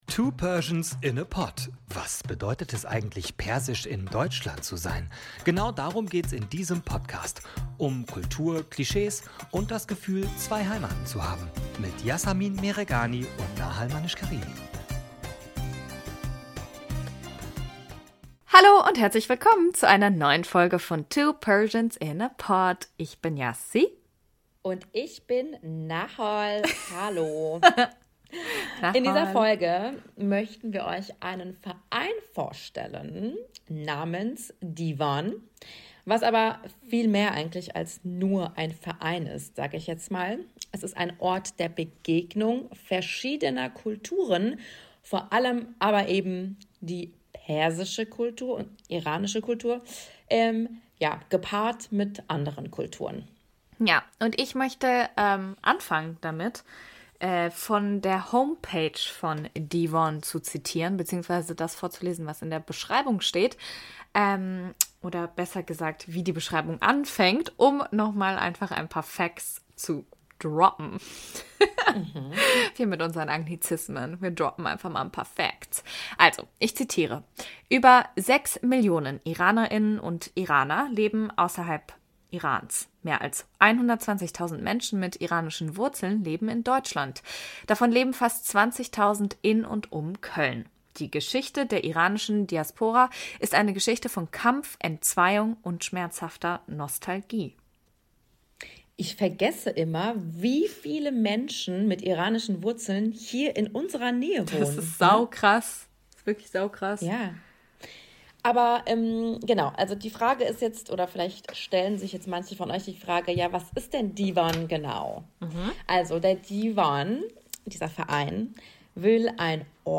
Und es wird sehr sehr viel gelacht in dieser Folge.